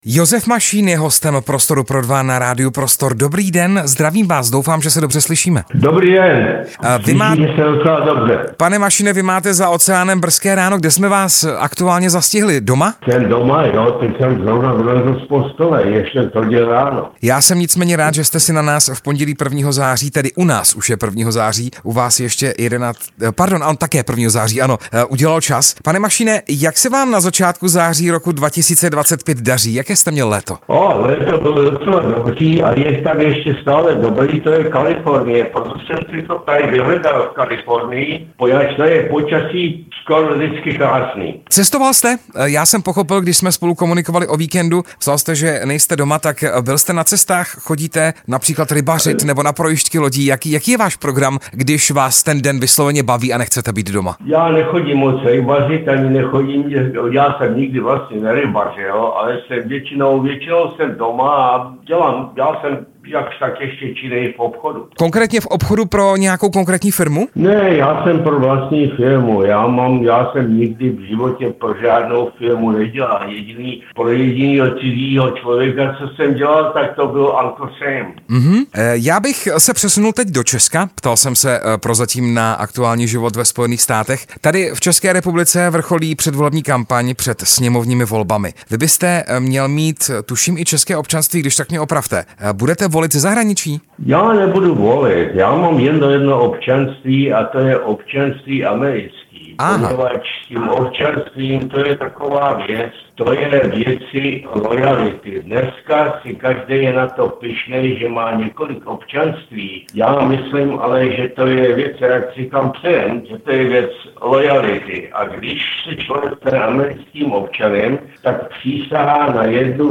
Hostem Prostoru pro dva byl v pondělí hrdina protikomunistického odboje Josef Mašín. V rozhovoru, který jsme vysílali živě z USA, kde Mašín žije, mimo jiné řekl, že by pro Česko bylo lepší, kdyby odešlo z Evropské unie. Kritizoval také současného prezidenta Petra Pavla i další české politiky.